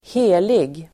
Uttal: [²h'e:lig]